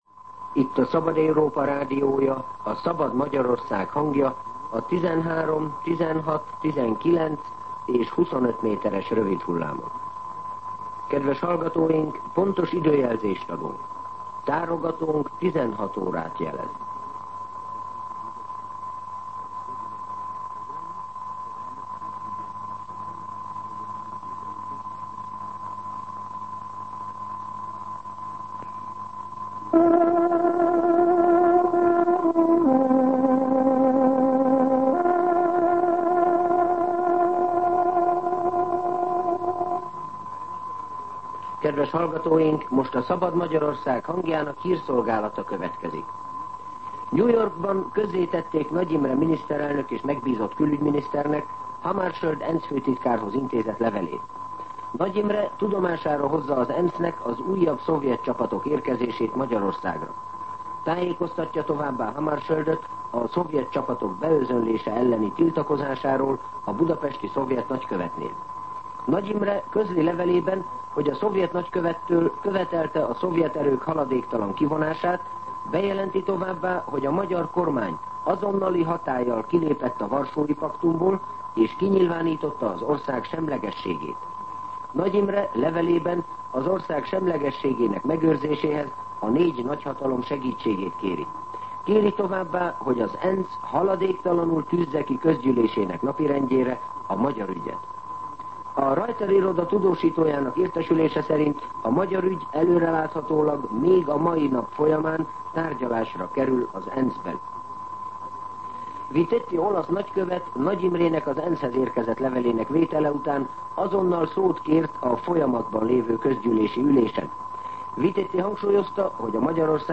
16:00 óra. Hírszolgálat